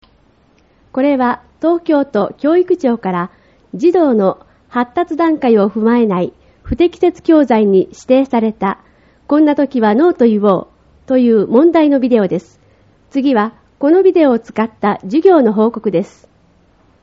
音声による説明 　これは、東京都教育庁から｢児童の発達段階を踏まえない不適切教材｣に指定された｢こんなときはノーといおう!｣(アー二出版)という、問題のビデオです。